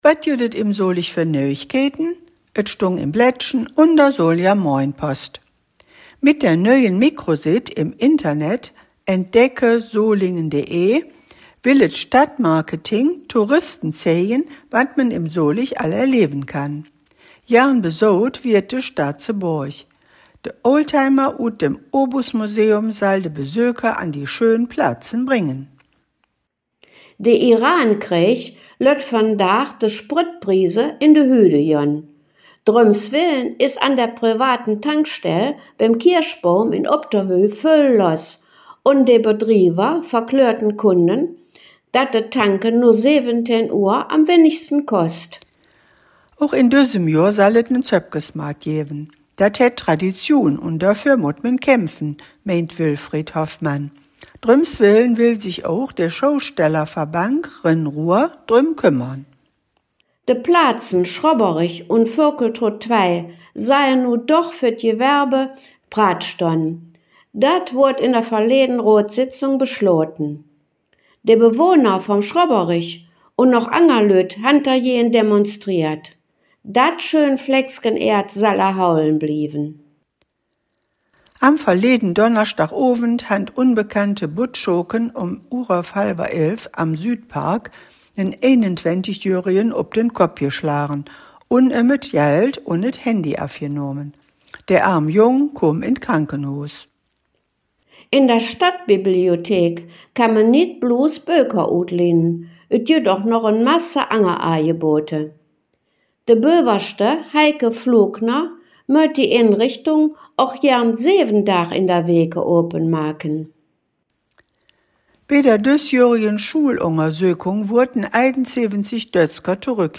Folge 274 der Nachrichten in Solinger Platt von den Hangkgeschmedden: Themen u.a.: Zöppkesmaart, Sozialkouphus, Nöümaart